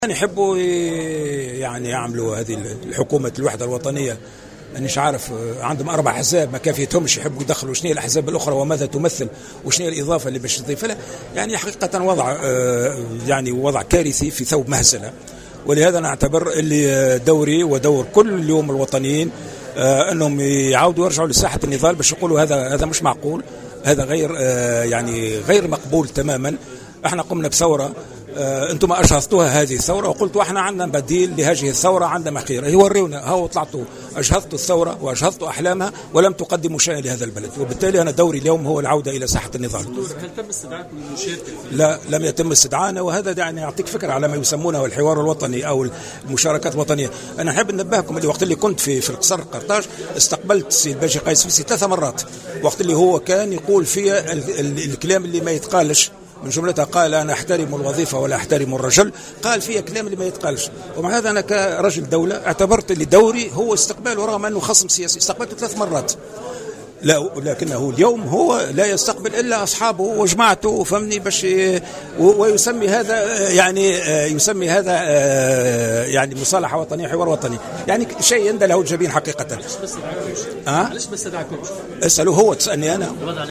واعتبر على هامش اشرافه اليوم السبت على افتتاح مكتب جديد لحزبه بمدينة القيروان، اعتبر مبادرة حكومة الوحدة الوطنية غير واضحة متسائلا عن الإضافة التي ستحققها.